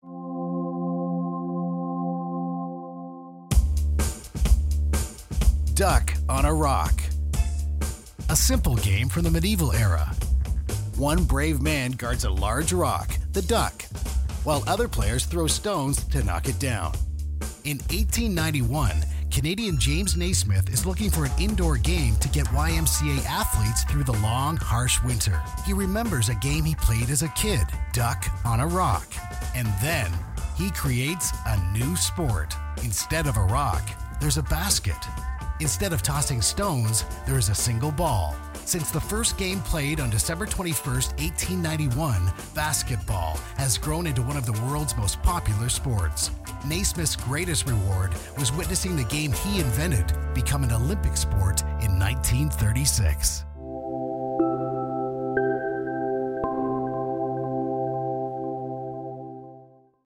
The sound I naturally have is edgy, fresh, fun and every day Joe.,
English (North American)
Middle Aged